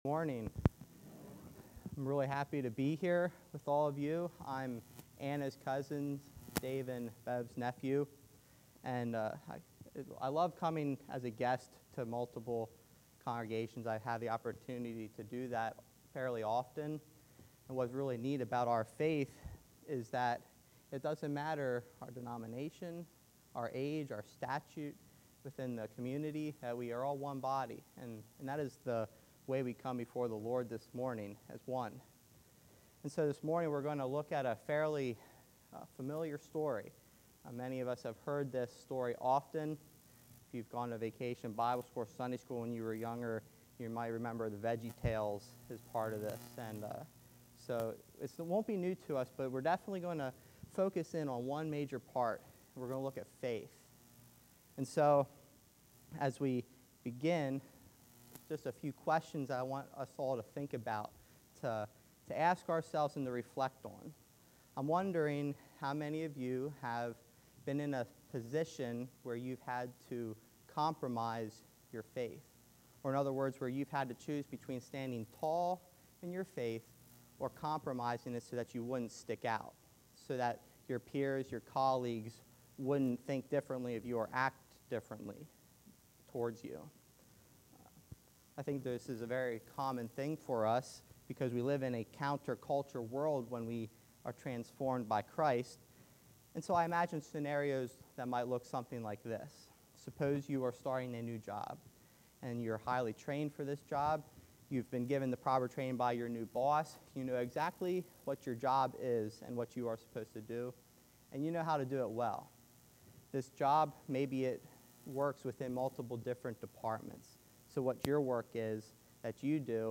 Message: “An ‘If Then’ Faith”